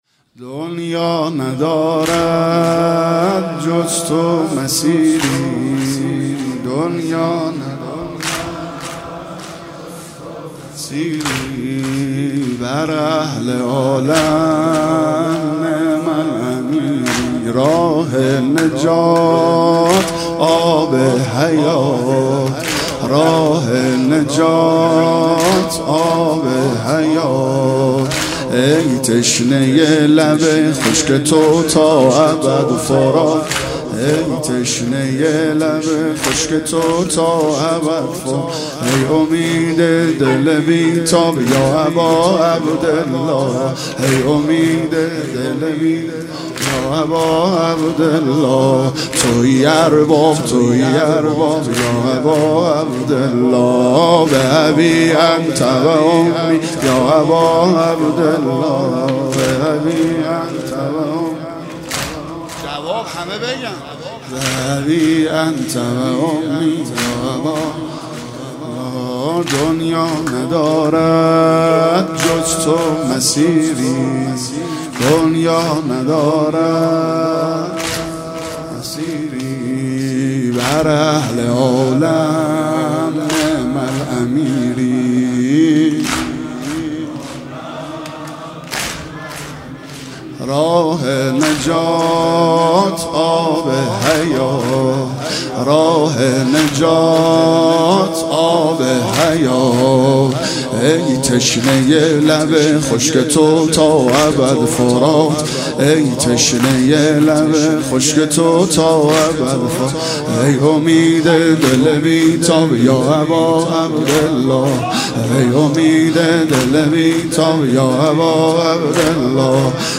صوت مراسم شب دوم محرم